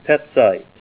Help on Name Pronunciation: Name Pronunciation: Petzite
Say PETZITE Help on Synonym: Synonym: ICSD 41772   Kurilite - hessite or petzite (?)